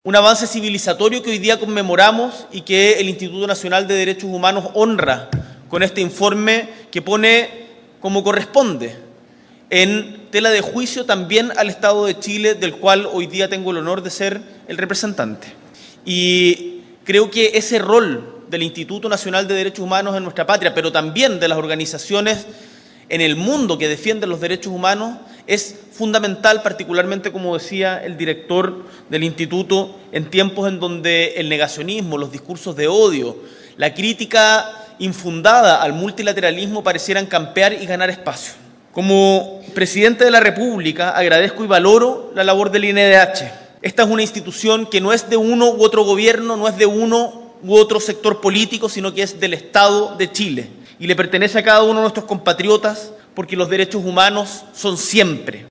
La ceremonia se realizó en el Centro Cultural Matucana 100 y contó con la presencia del presidente Gabriel Boric.